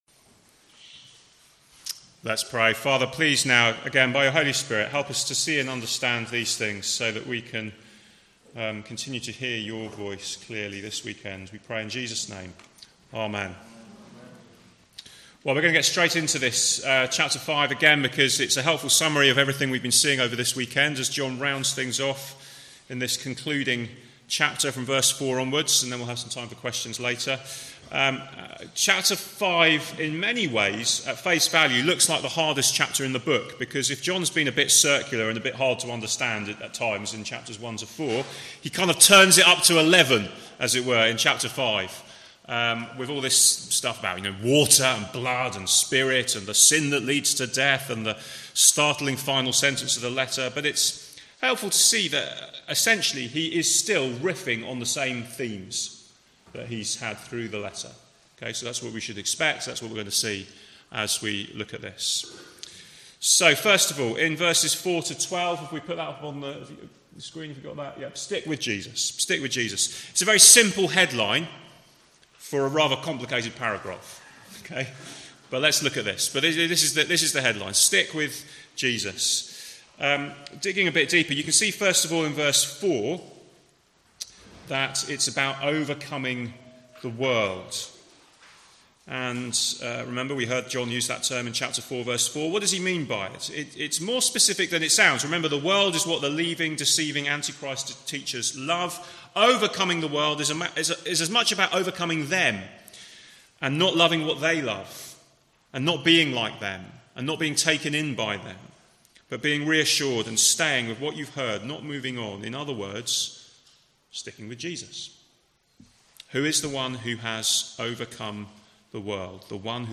Series: Weekend Away July 2021 | 1 John